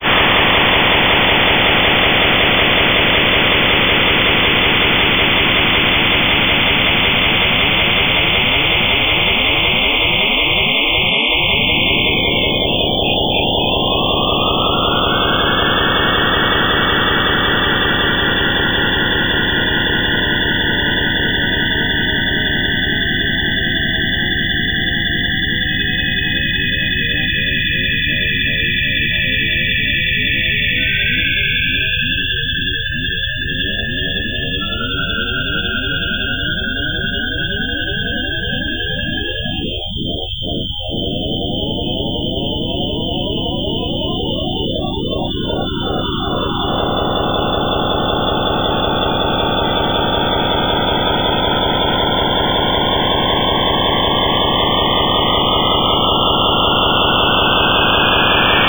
Plot Sonification